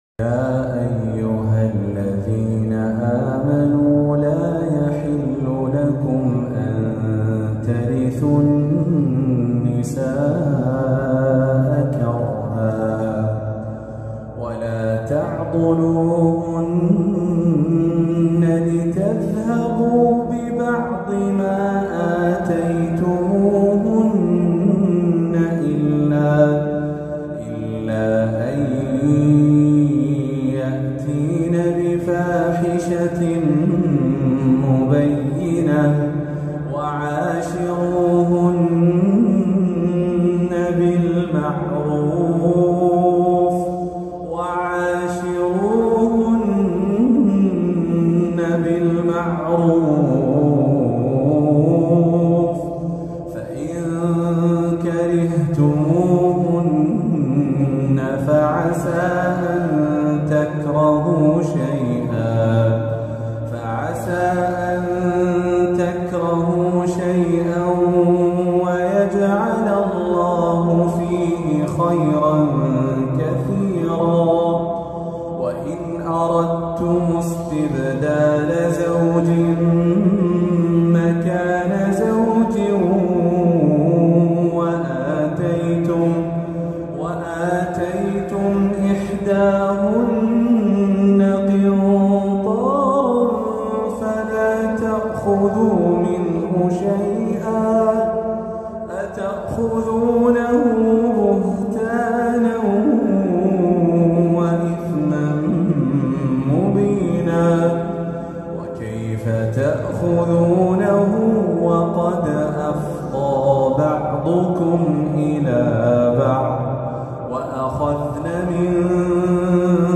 جديد - تلاوة هادئة
تلاوة هادئة خاشعة من سورة النساء
جامع القاضي ، الرياض